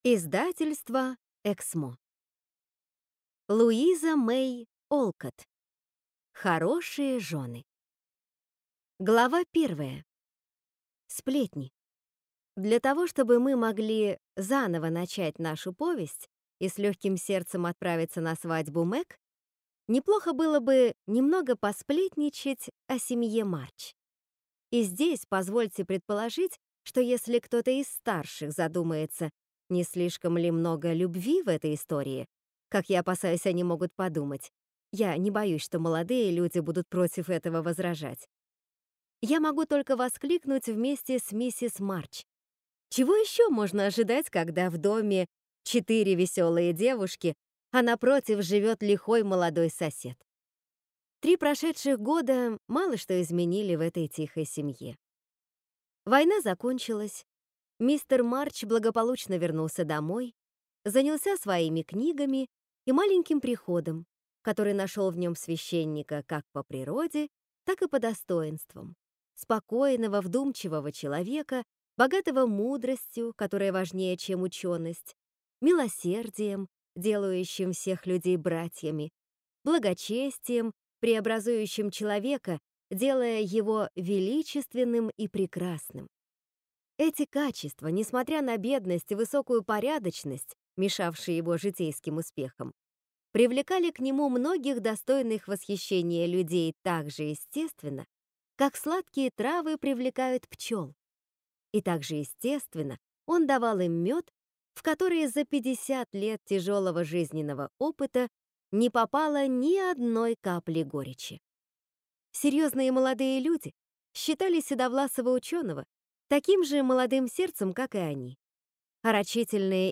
Аудиокнига Хорошие жены | Библиотека аудиокниг